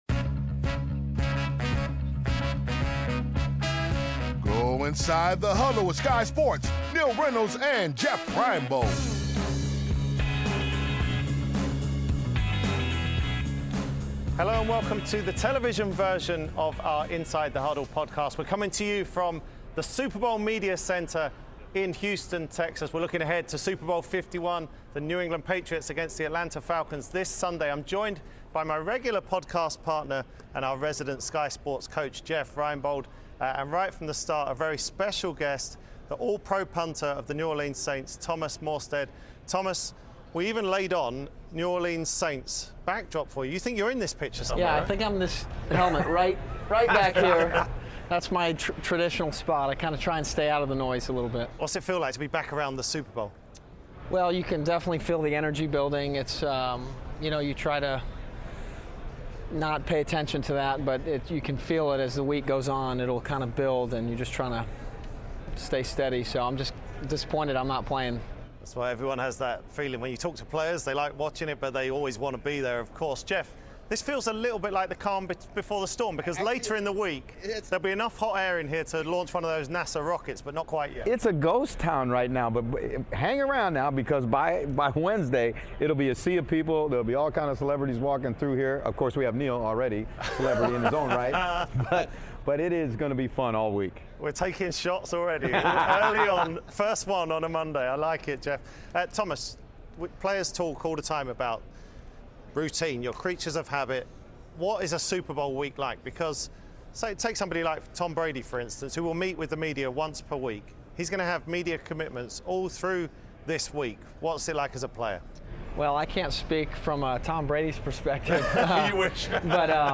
Inside the Huddle: Live from Houston